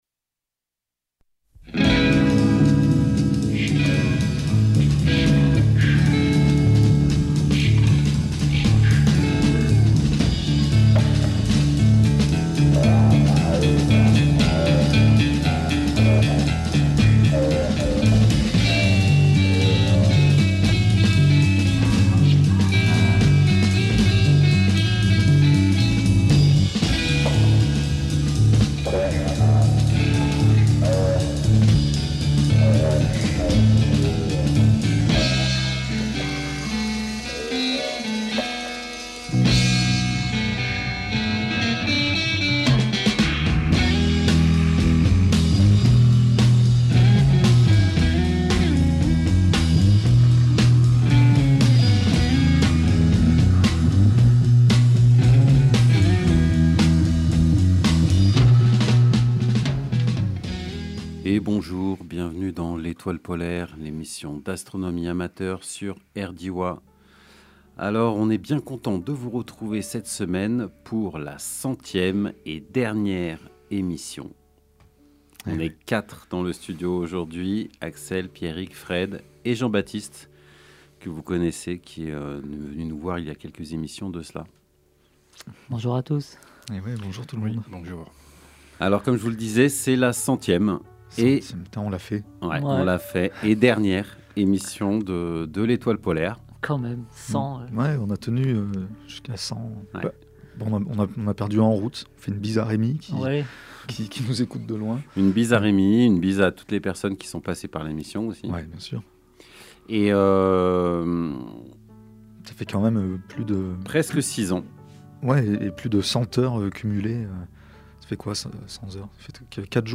Télécharger en MP3 Pour la dernière de l’Etoile Polaire : Emission spéciale débat sur la science … ses réussites, ses limites…